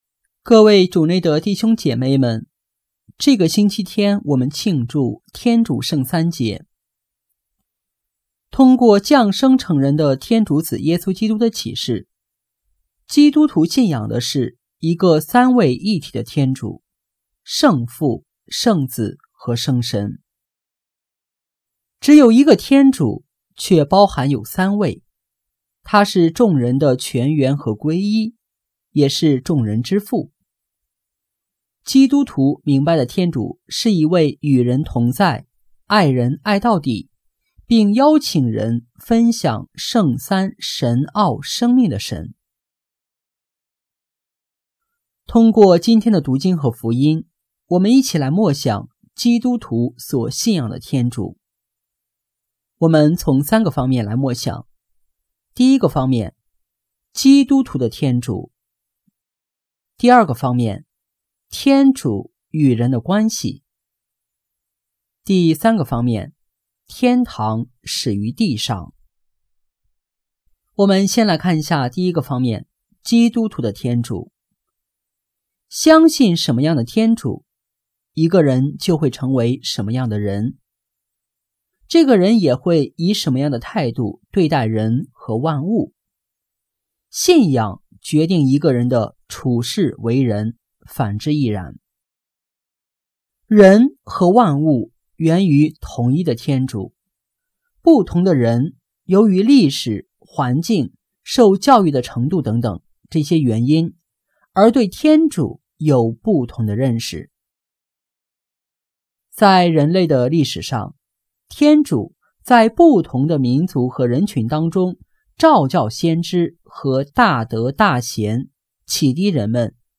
【主日证道】| 圣三爱人始于地（天主圣三节）